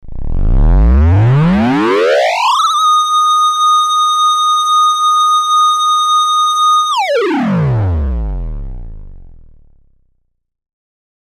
Forcefields
Pulsating force fields.